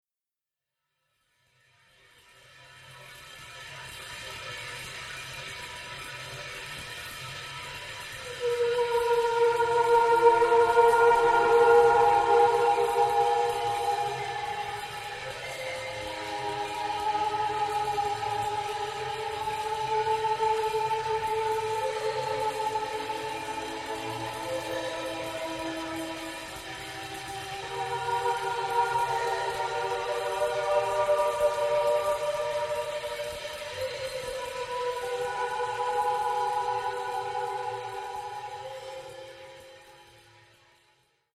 Voice, Marimba, Vibraphone